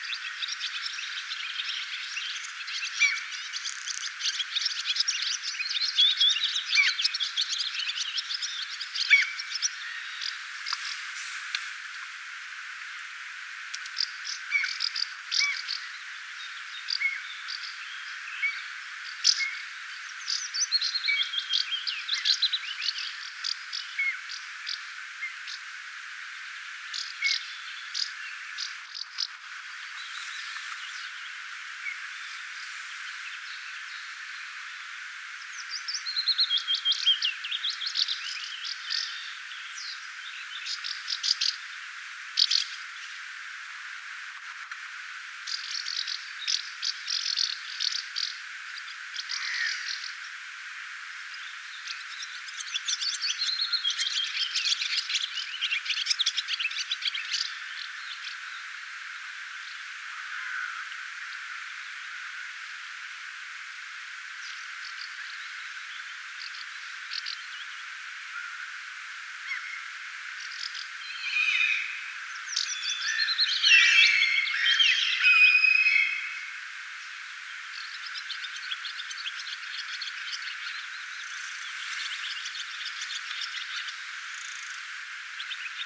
pääskysten ääntelyä
paaskysten_aantelya_haara_ja_raystaspaaskyja.mp3